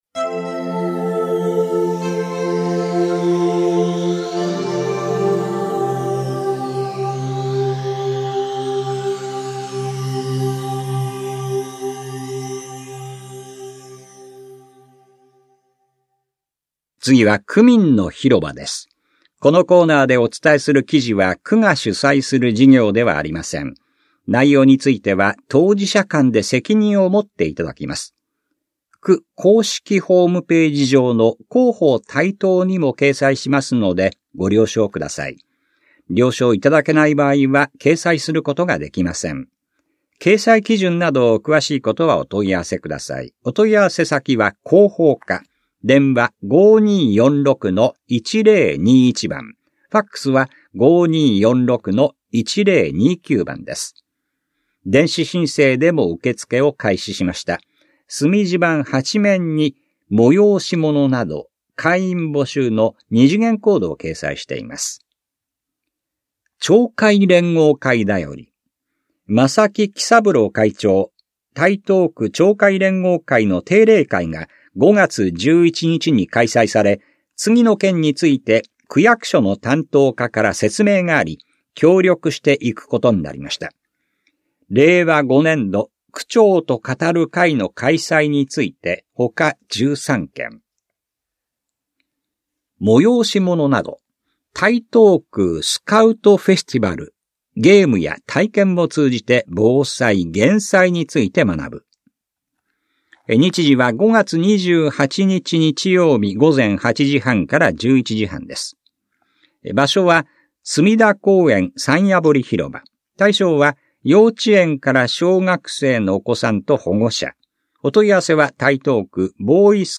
広報「たいとう」令和5年5月20日号の音声読み上げデータです。